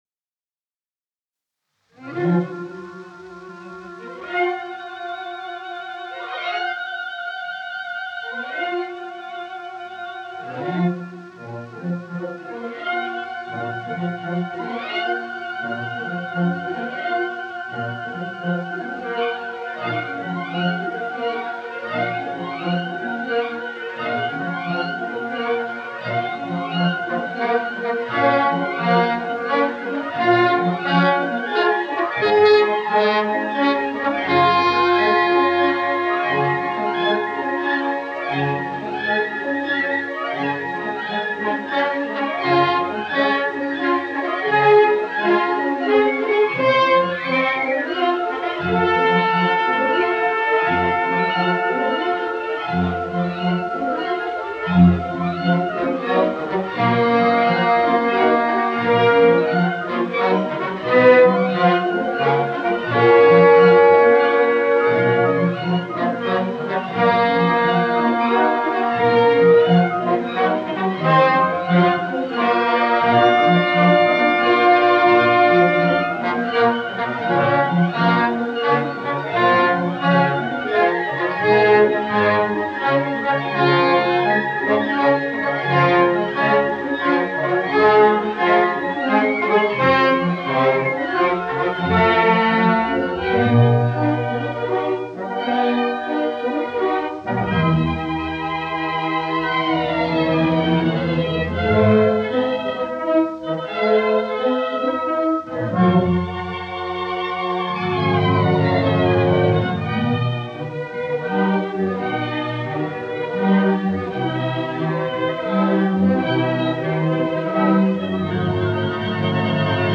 Richard Wagner: Die Walküre, WWV 86B, The Ride of the Valkyries (Berlin State Opera Orchestra) (1926)
We discussed the difference between electrical and acoustic recordings: electrical recordings are much like today’s recordings, made with microphones and amplifiers; acoustic recordings were the ones made in small rooms with large recording horns.
We may have an initial difficulty in listening to an orchestral sound that is far different from today, but it’s in the details that the value is found.